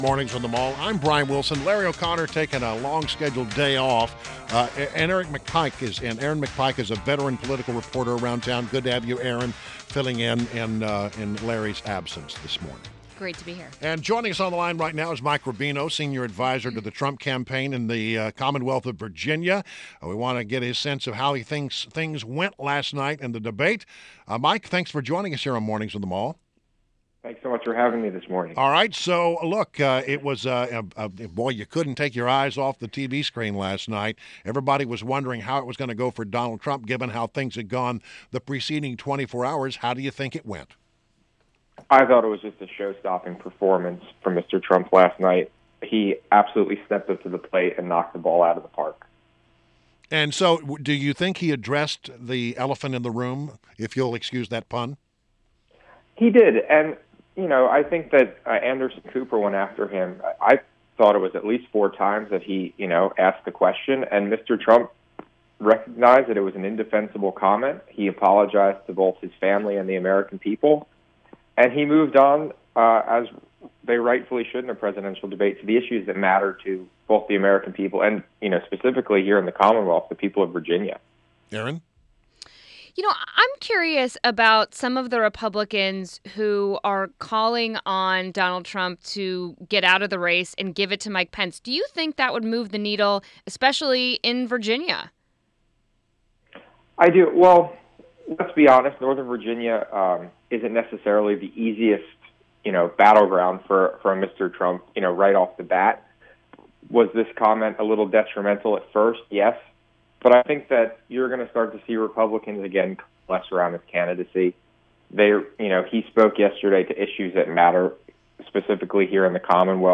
WMAL Interview